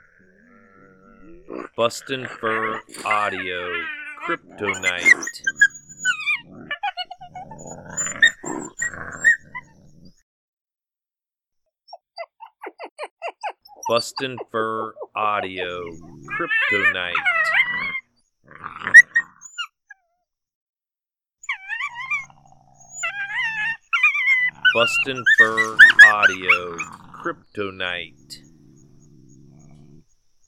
Male (Apollo) and Female (Beans) Coyotes courting before breeding, she's not ready but he is and the struggle for him begins. Growls, Yips and Whimpers make this sound deadly on the Coyotes you're calling to.
• Product Code: pups and fights